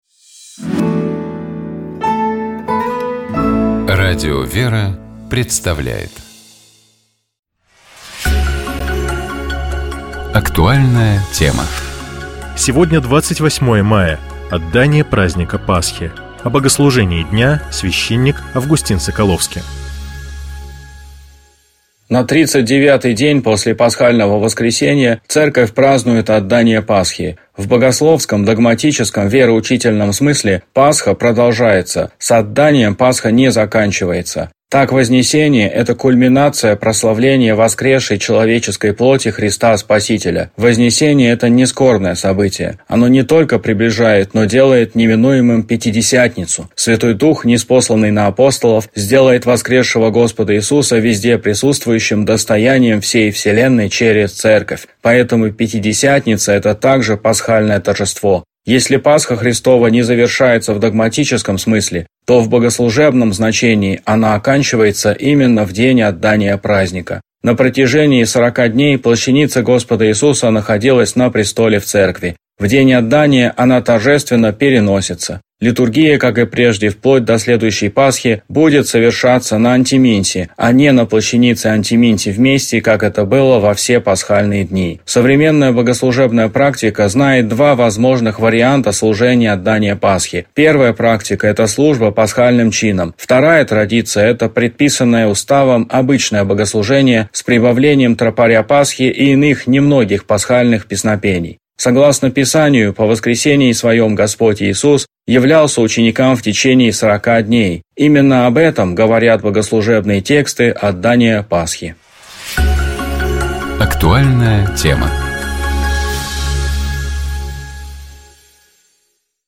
Комментирует священник